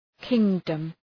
{‘kıŋdəm}